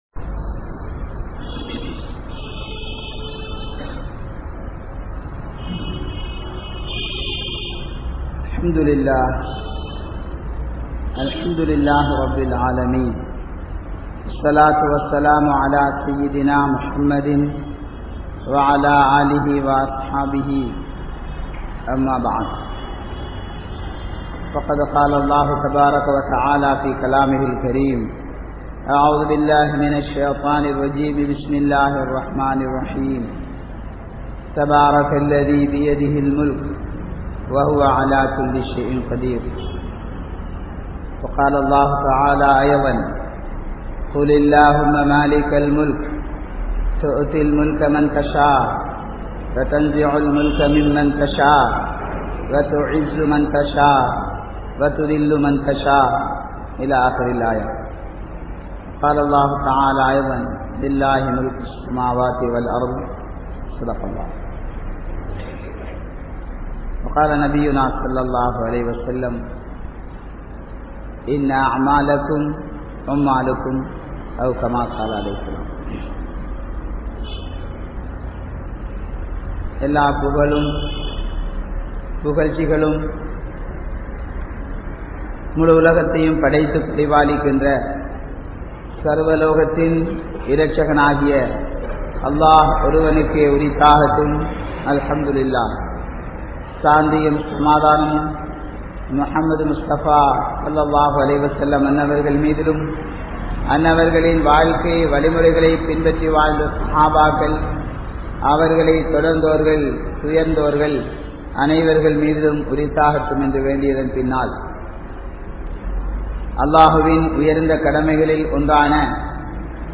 Sirantha Aatsiyaalan Yaar? (சிறந்த ஆட்சியாளன் யார்??) | Audio Bayans | All Ceylon Muslim Youth Community | Addalaichenai